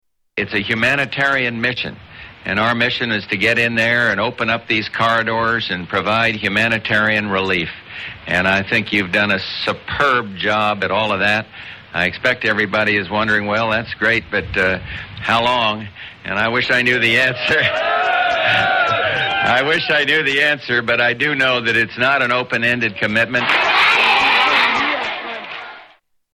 Bush addresses US troops in Somalia